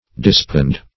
Search Result for " dispand" : The Collaborative International Dictionary of English v.0.48: Dispand \Dis*pand"\, v. t. [L. dispandere to spread out; pref. dis- + pandere, pansum, to spread out.] To spread out; to expand.